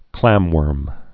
(klămwûrm)